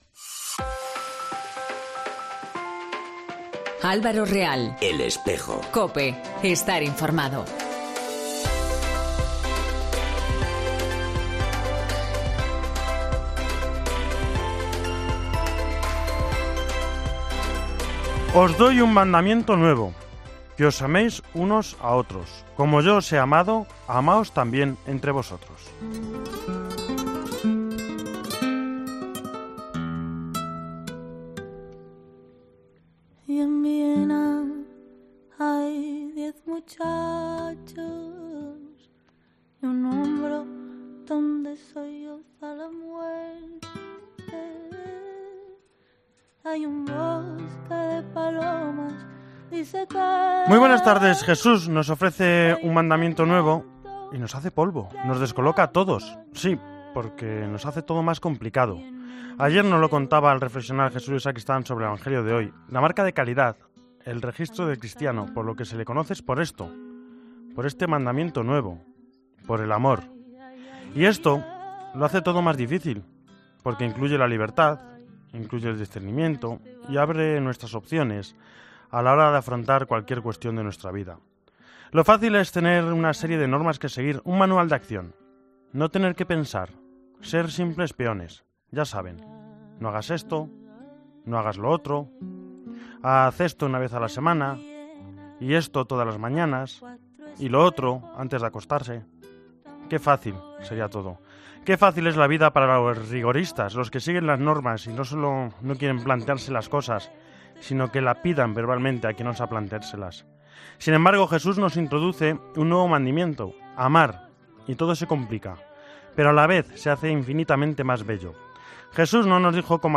Tema-entrevista del día e Hispanoamérica.